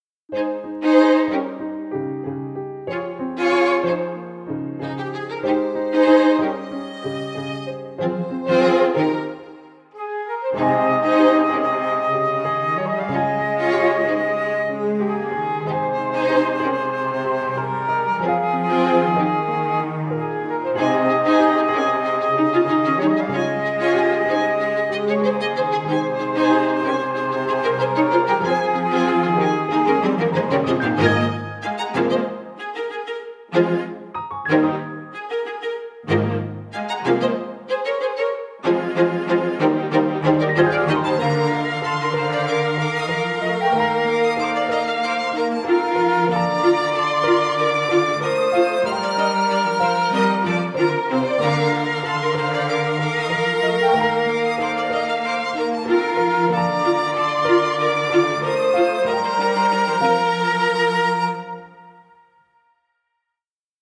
ストリングスカルテット+ピアノ+フルートの6パートによる小曲です。
クラシック風のBGMが欲しいけど純クラシックはちょっとというときにいかがでしょう。
小編成
クラシック風 ストリングスカルテット+ピアノ+フルートの6パートによる小曲です。